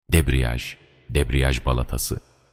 clutch-page-in-turkish.mp3